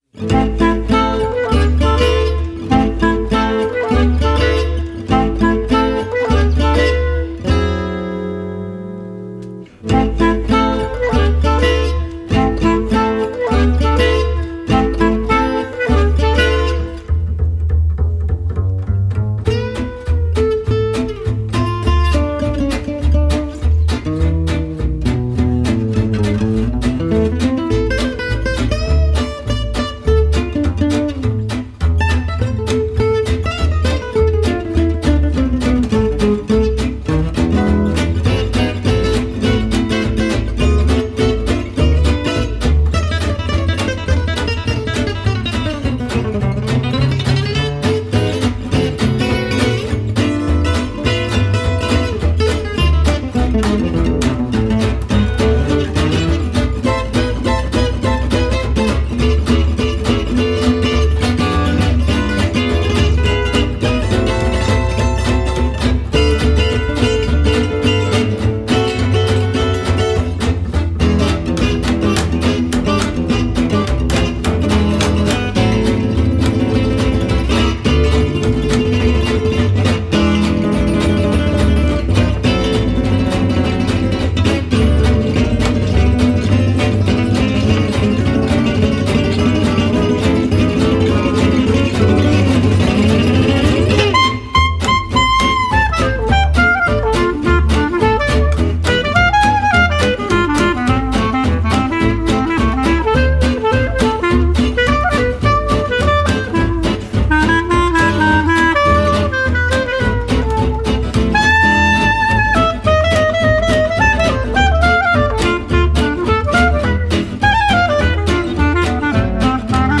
А так как мне почти всегда нравится своеобразное триединство ( клипповость)) - изображение (в данном случае -фото) + соответствующая музыка +  слова (найти самые подходящие слова - чуть ли не самое сложное)),  то и появилась ещё  и музыка - между спокойной оркестровой и джазом - предпочтение отдано джазу - а комментарии показались  ненужными.))